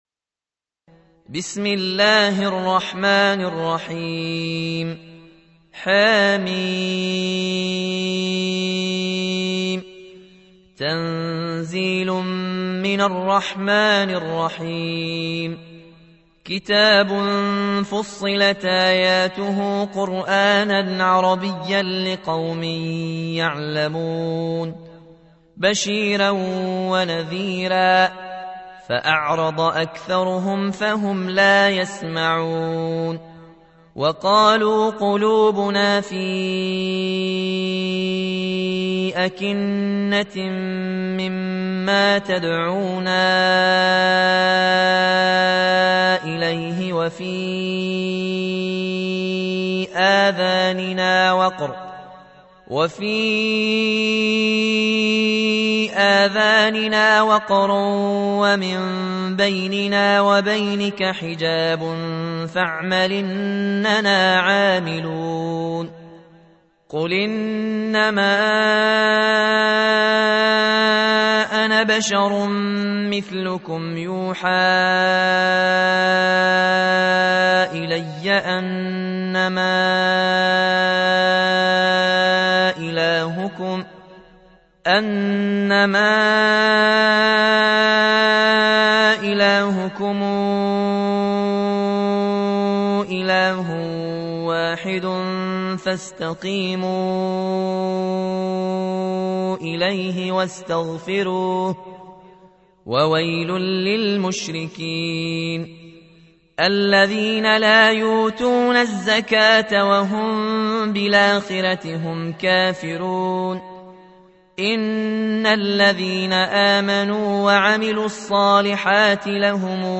تحميل : 41. سورة فصلت / القارئ ياسين الجزائري / القرآن الكريم / موقع يا حسين